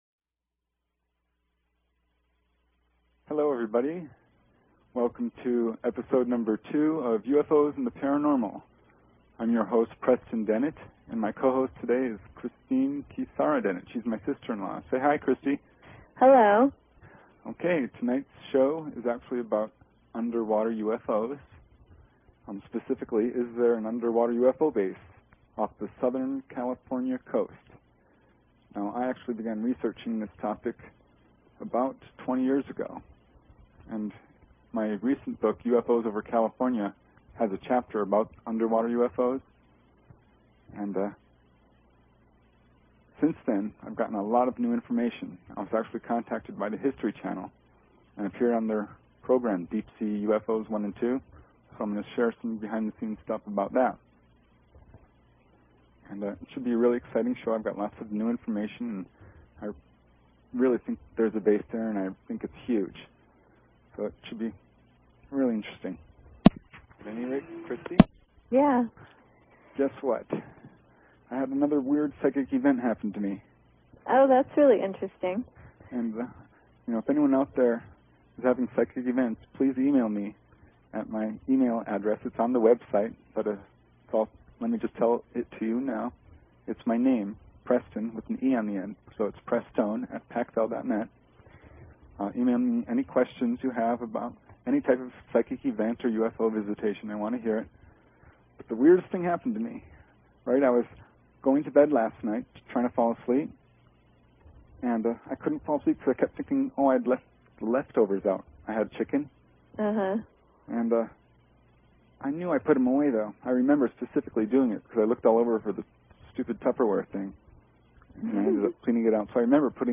Talk Show Episode, Audio Podcast, UFOs_and_the_Paranormal and Courtesy of BBS Radio on , show guests , about , categorized as